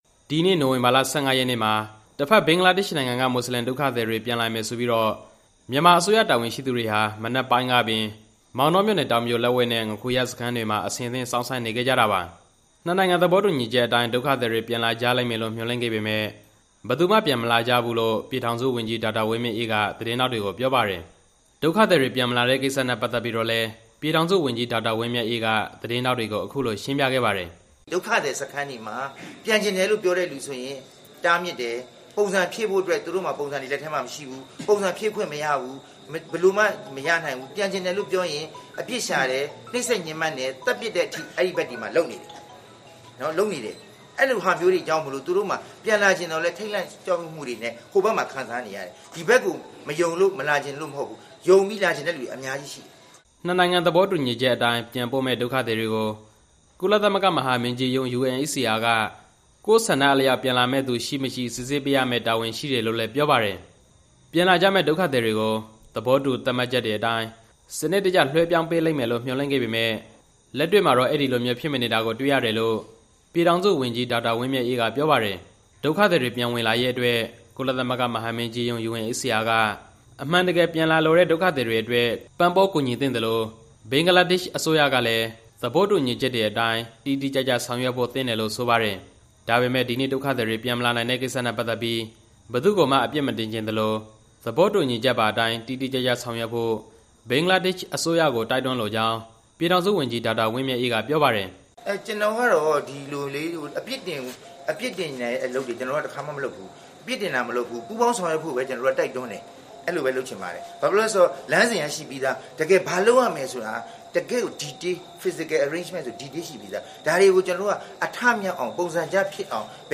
ရခိုင်ပြည်နယ်ကနေ ဘင်္ဂလားဒေ့ရ်ှနိုင်ငံဘက် ထွက်ပြေးသွားတဲ့ ဒုက္ခသည်တွေ ပြန်လက်ခံရေး နှစ်နိုင်ငံ ပူးတွဲအစီအစဉ်အရ ဒီနေ့ နိုဝင်ဘာလ ၁၅ ရက်နေ့မှာ မြန်မာဘက်က စောင့်ဆိုင်းခဲ့ကြပေမယ့်  ပြန်မလာကြတဲ့ကိစ္စနဲ့ပတ်သက်ပြီး လူမှုဝန်ထမ်း ကယ်ဆယ်ရေးနဲ့ ပြန်လည်နေရာချထားရေးဝန်ကြီး ဒေါက်တာဝင်းမြတ်အေးက စစ်တွေမြို့မှာ သတင်းထောက်တွေကို ရှင်းလင်းခဲ့ပါတယ်။
ဒုက္ခသည်တွေပြန်မလာတဲ့ကိစ္စ သတင်းစာရှင်းလင်း